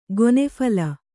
♪ gone phala